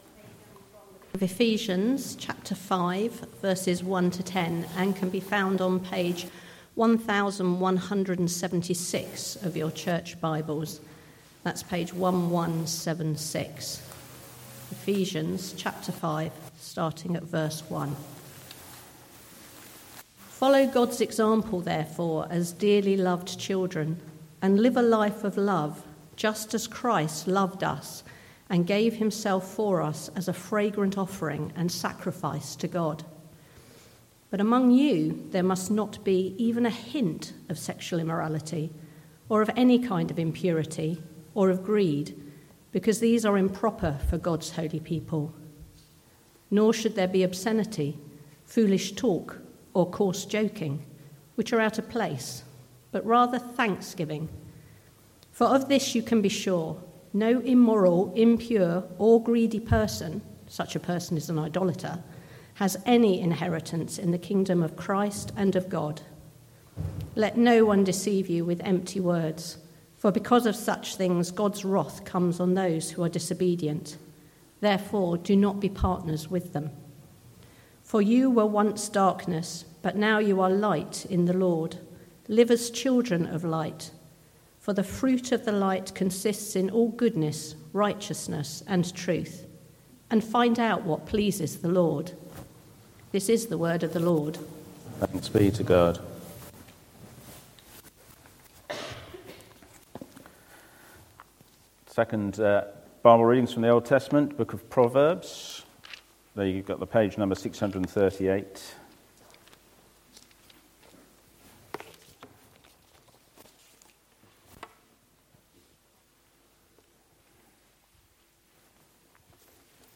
Sermons – Dagenham Parish Church
Service Type: Sunday Morning